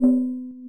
wateringcan.wav